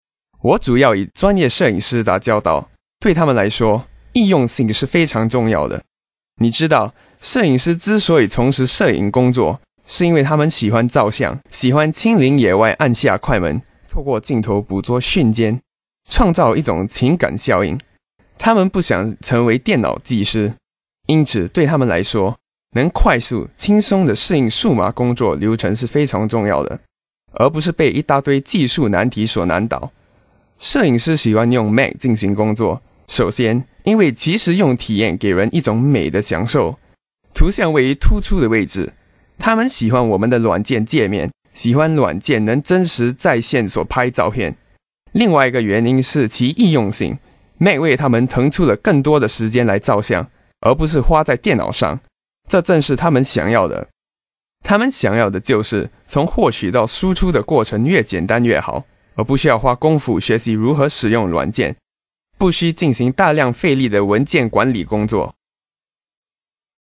Kein Dialekt
Sprechprobe: eLearning (Muttersprache):
chinese voice over artist